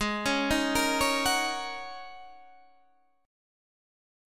G#7sus2sus4 chord